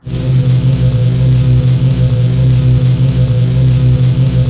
ENGINE_4.mp3